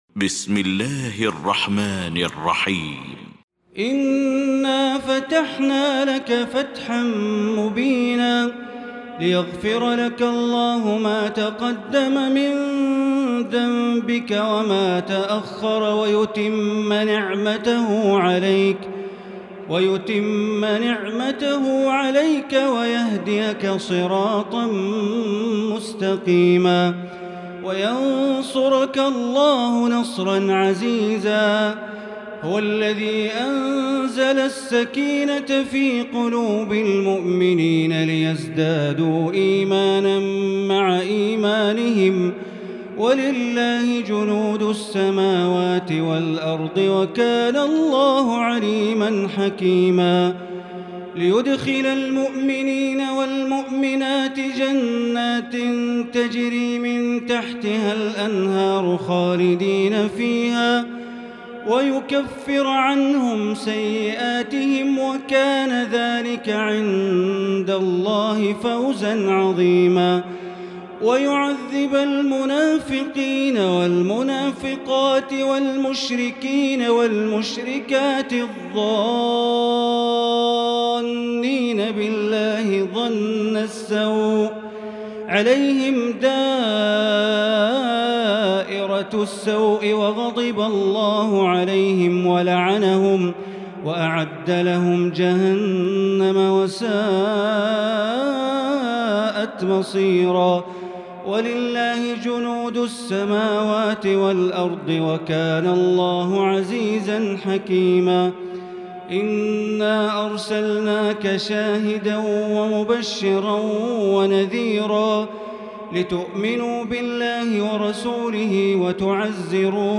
المكان: المسجد الحرام الشيخ: معالي الشيخ أ.د. بندر بليلة معالي الشيخ أ.د. بندر بليلة سعود الشريم الفتح